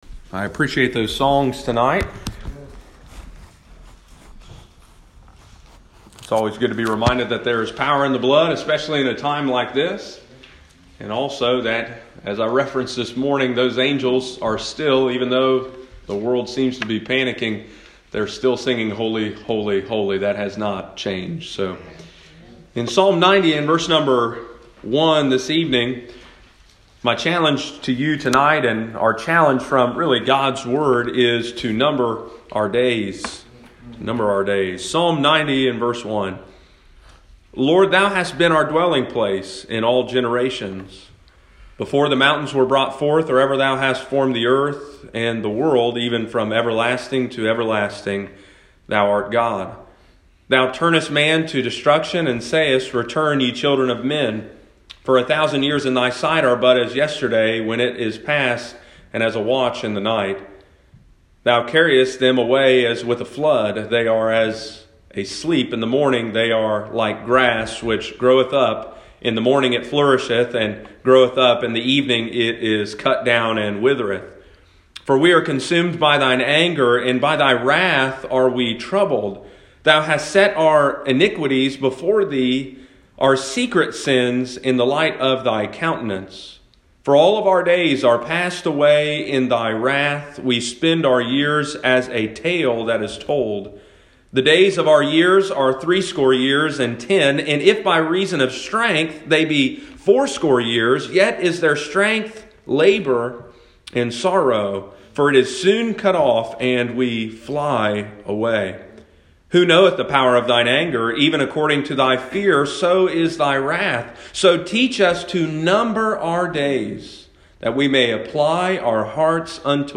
Sunday evening, March 15, 2020.